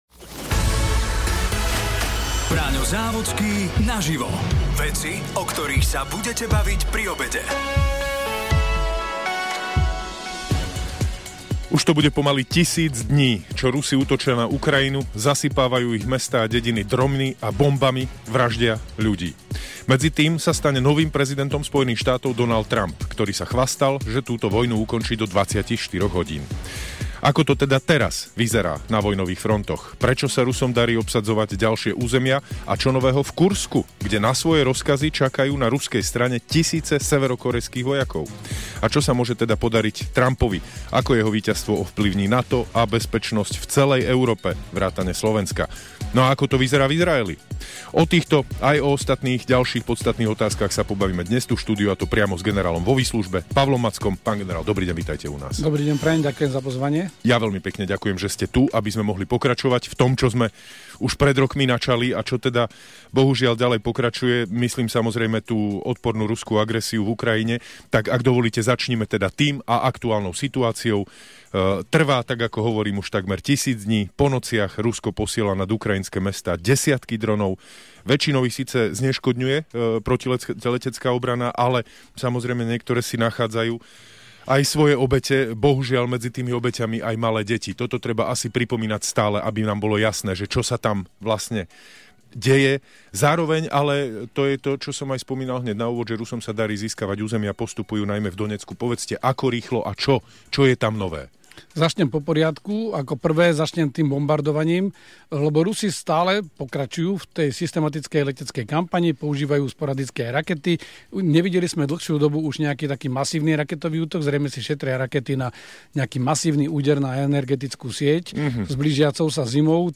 Jasné otázky – priame odpovede. Braňo Závodský a jeho hosť každý pracovný deň 10 minút po 12-tej naživo v Rádiu Expres na aktuálnu tému o veciach, o ktorých sa budete baviť pri obede.